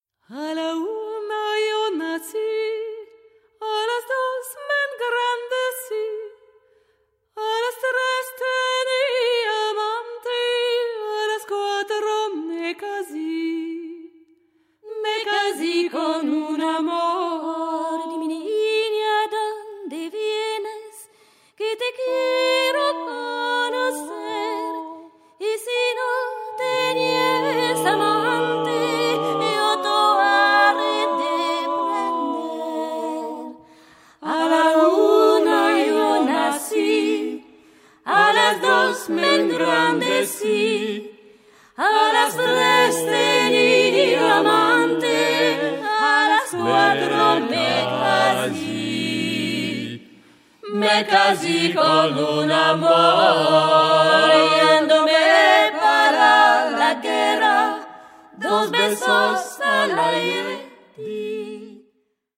Chants polyphoniques pour voix mixtes, harmonisés pour chœur
la basse et sa guitare.